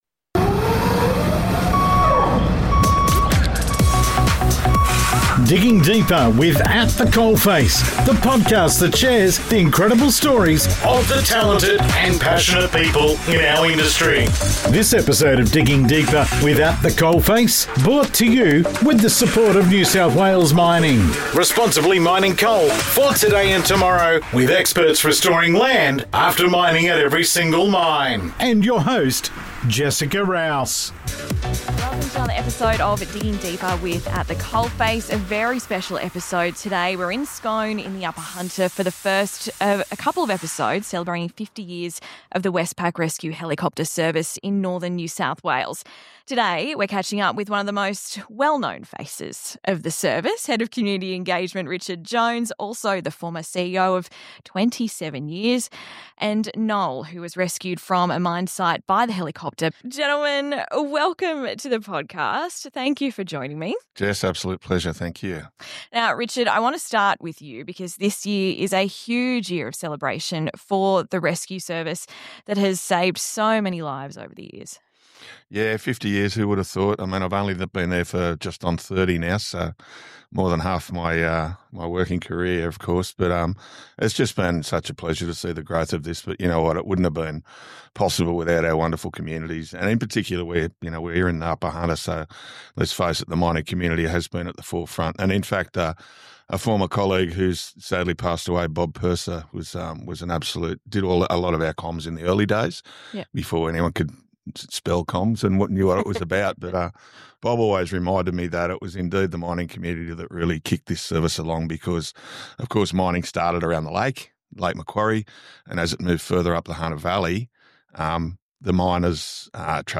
We often interview people and would love to share the WHOLE story with you, so here it is.